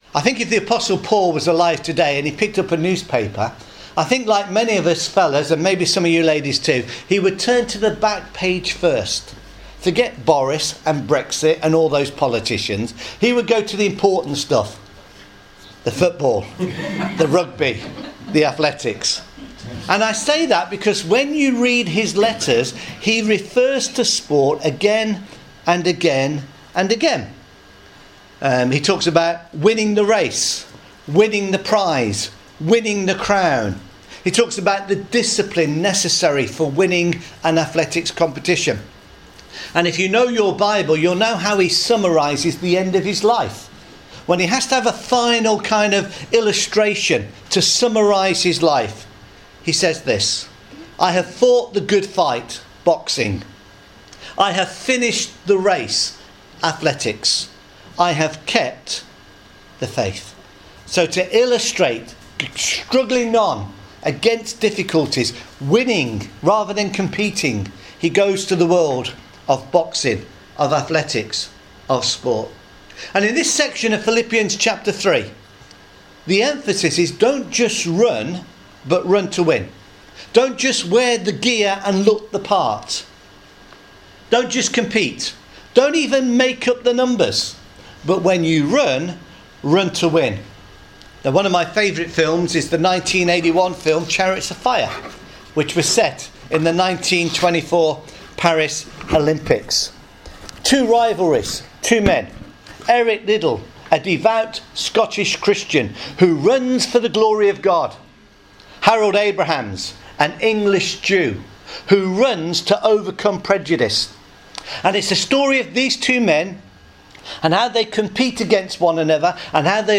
Philippians chapter 3 vs verses 12-16 – sermon